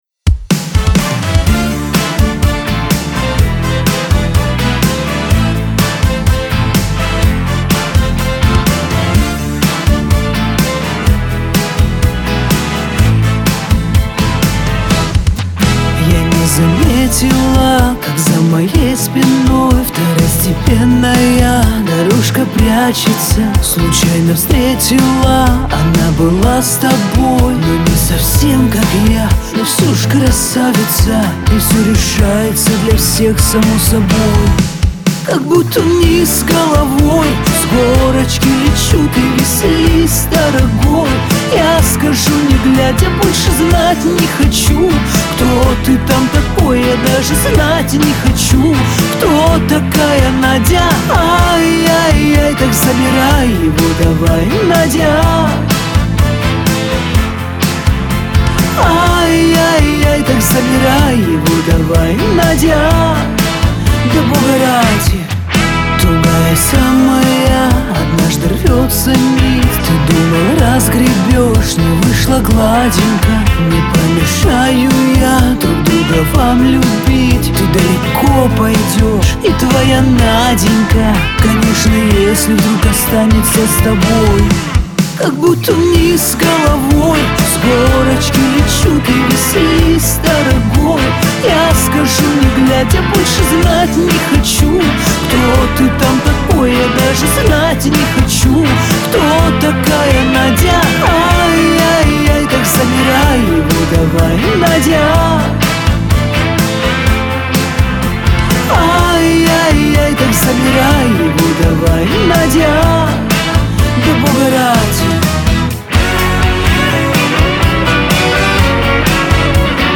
pop
Веселая музыка